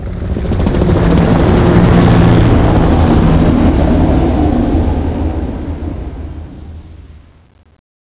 helicopter_flyby_01.wav